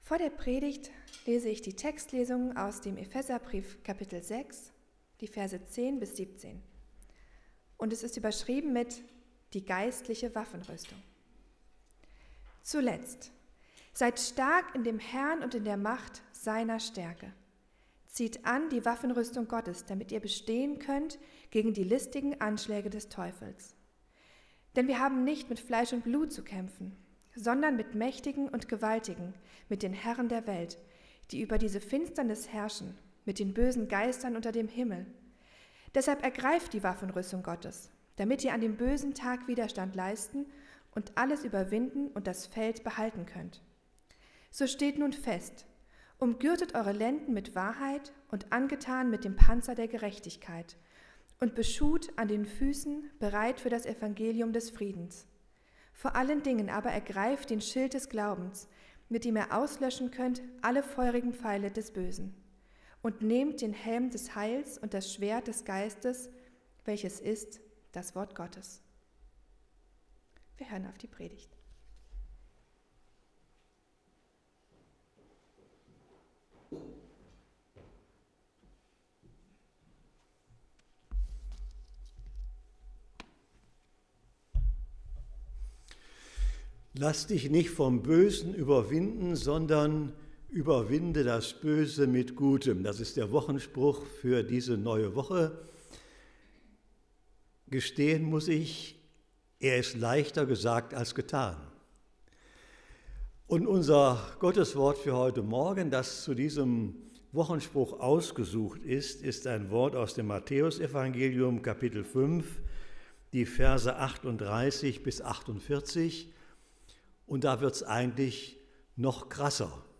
Gottesdienst | Ev.